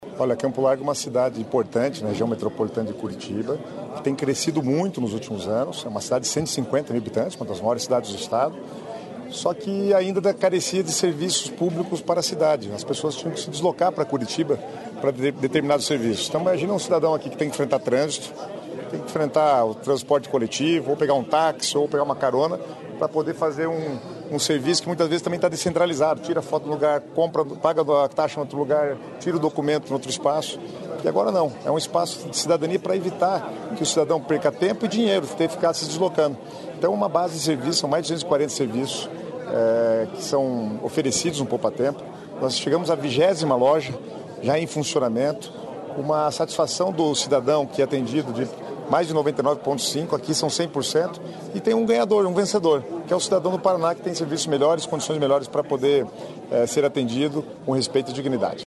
Sonora do secretário das Cidades, Guto Silva, sobre o novo Poupatempo em Campo Largo